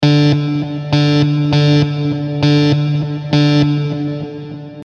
空袭警报器 (测试, V2)
描述：旧金山紧急警报器的另一个录音。这次用我的Tascam录制，因此录制的是立体声和高品质。
标签： 警告 空袭 国防 龙卷风 空气 公民 联邦紧急情况 灾难突袭 警笛
声道立体声